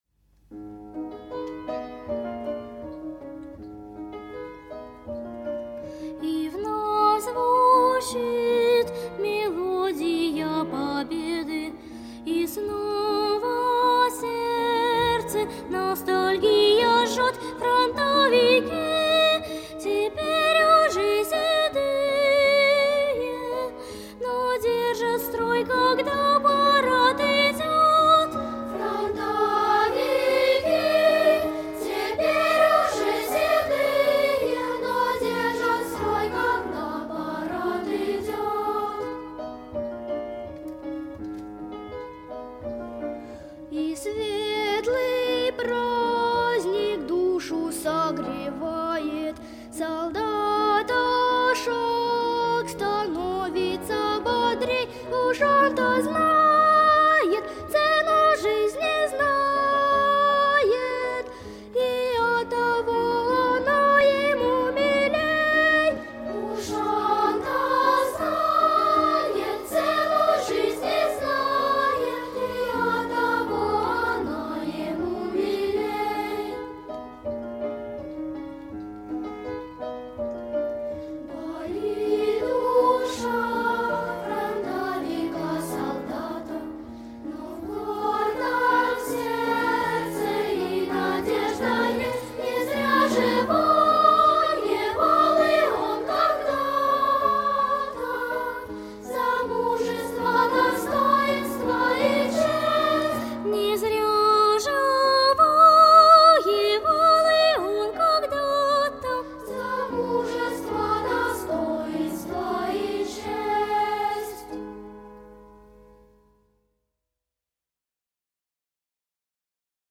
• Название: Детская песня
• Жанр: Детские песни